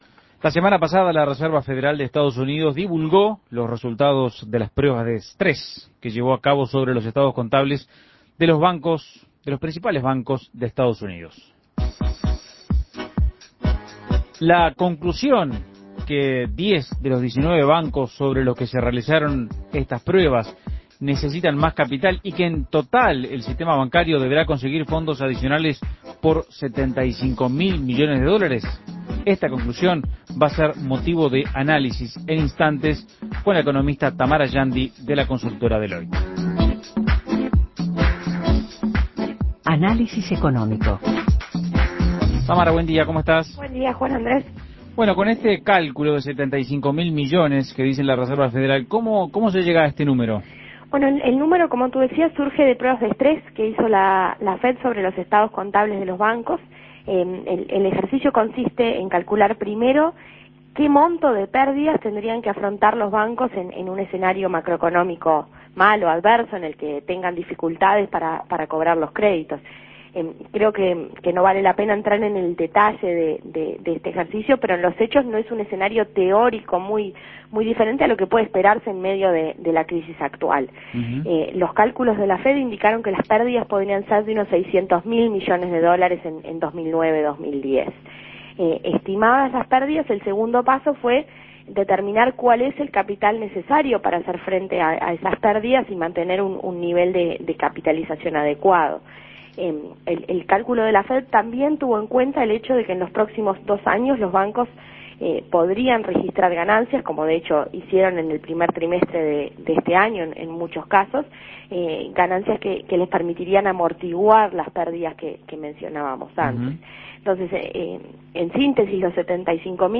Análisis Económico ¿Cómo se interpretan las últimas señales provenientes de los mercados internacionales?